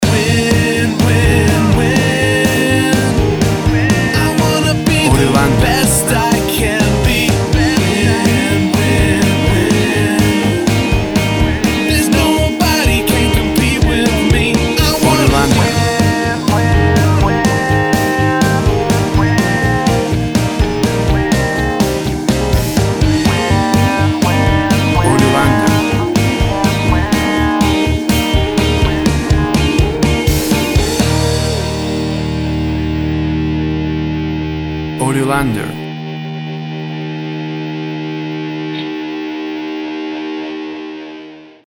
Tempo (BPM) 124